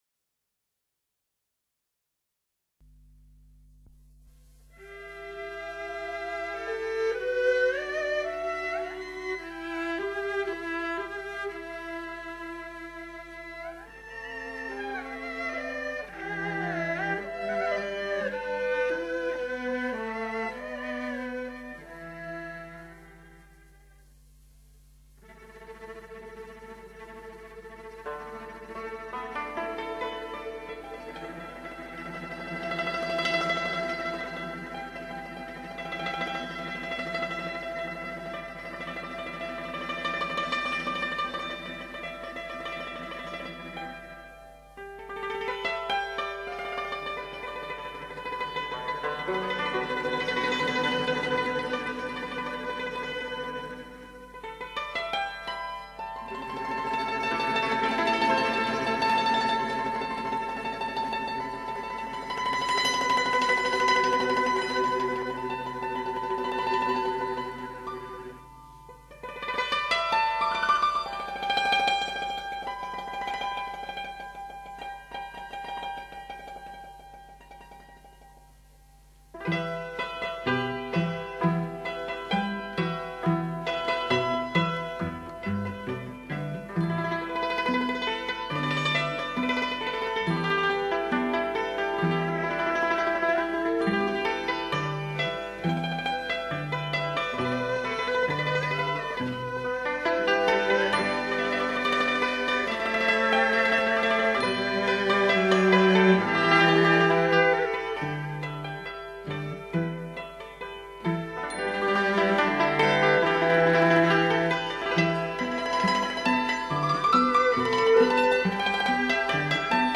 大多数的弹拨乐器都具有音色明亮、清脆的特点，其弹奏方法
迂回婉转的音律，卓越深厚的演奏、憾人肺腑的乐魂、